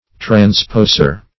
Transposer \Trans*pos"er\, n. One who transposes.